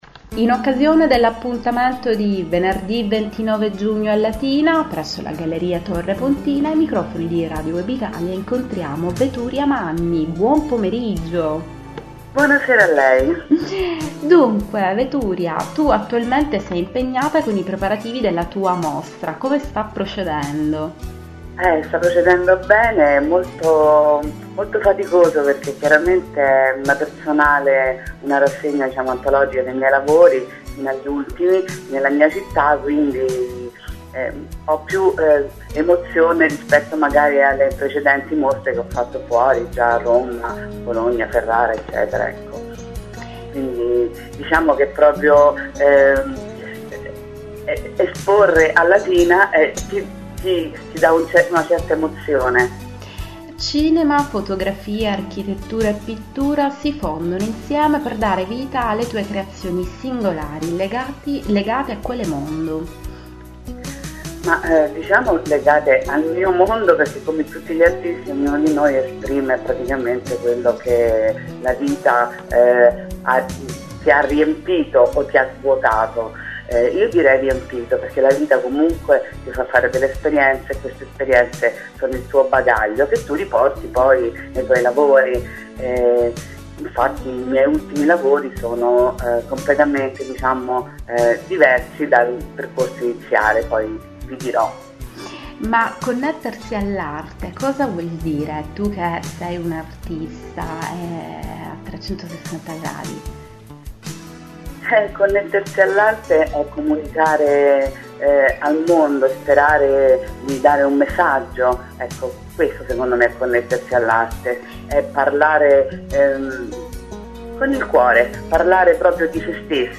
intervista su Radio Web Italia